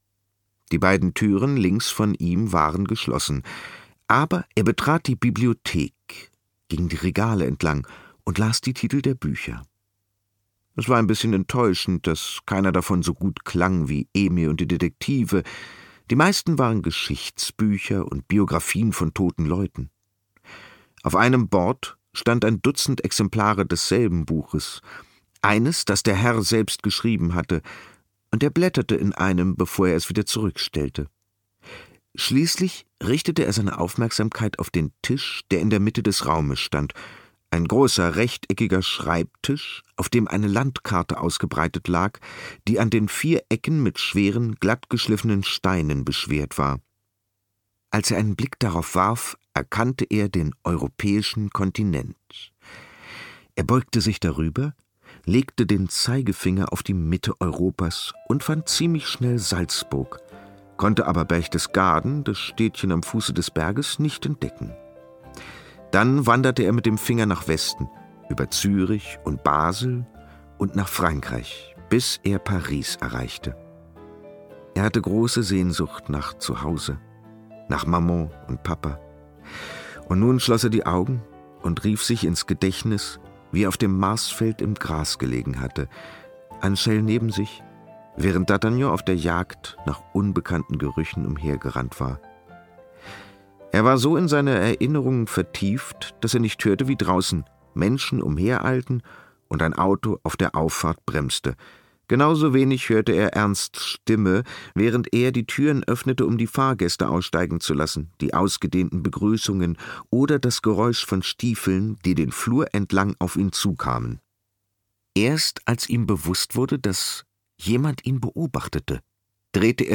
Der Junge auf dem Berg John Boyne (Autor) Boris Aljinovic (Sprecher) Audio-CD 2017 | 2.